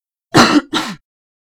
sound-cough1
cough disease fun ill sound effect free sound royalty free Memes